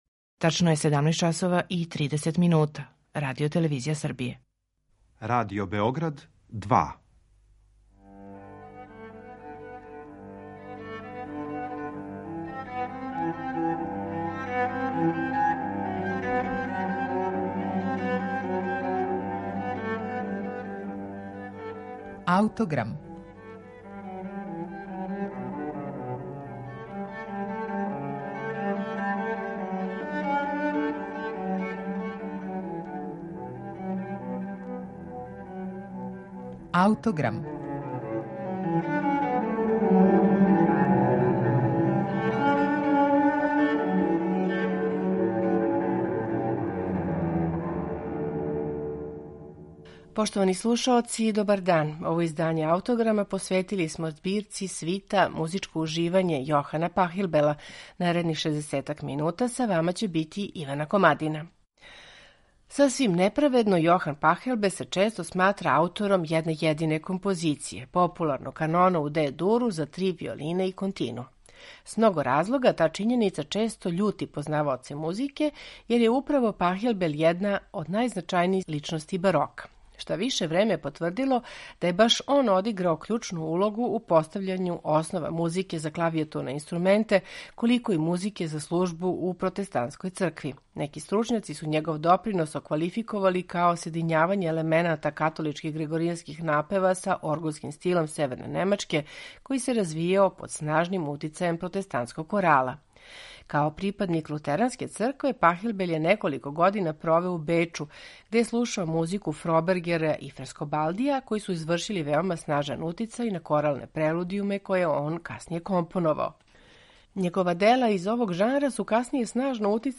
У данашњем Аутограму Пахелбелове свите „Музичко уживање" слушамо у интерпретацији чланова ансамбла „Лондон барок".